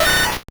Cri de Sabelette dans Pokémon Or et Argent.